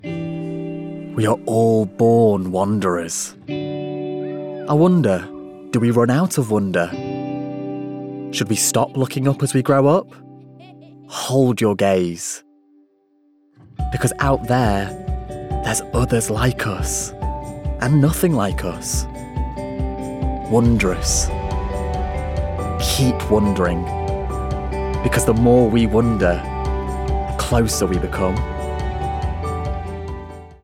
Voice Reel
Heathrow - Soft, Wondrous